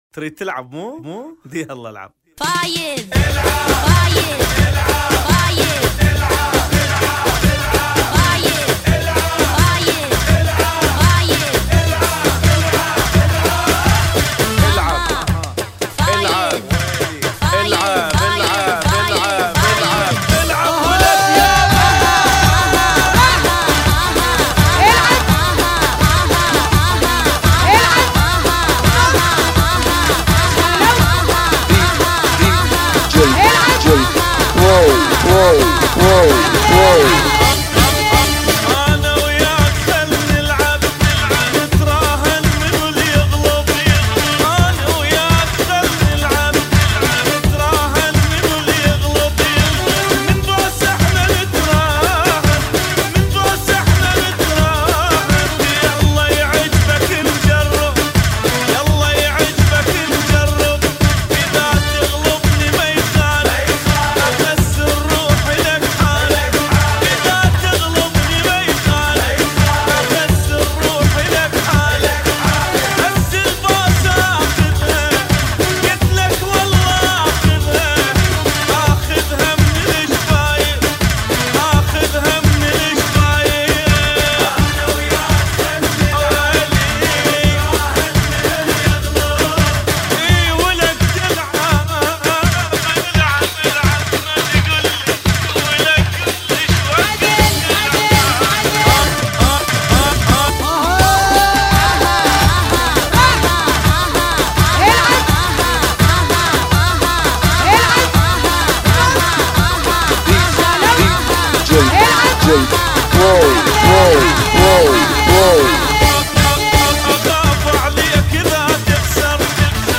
iRaQy Style